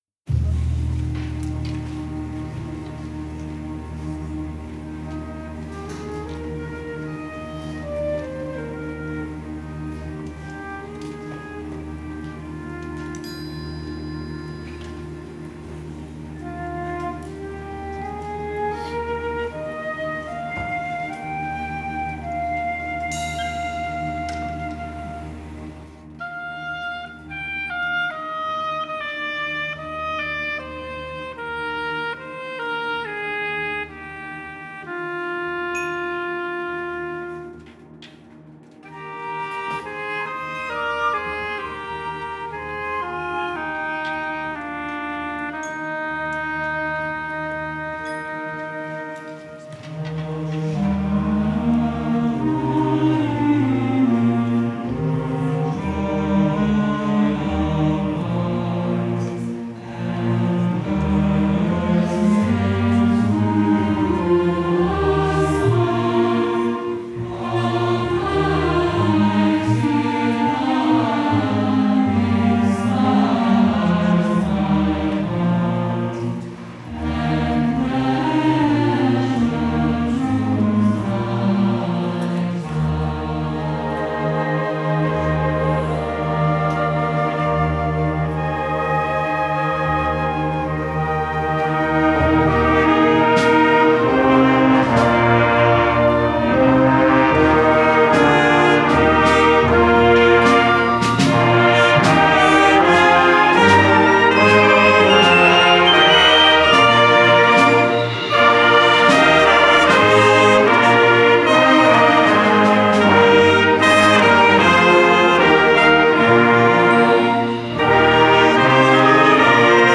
Spring Music Festival
Combined Ensembles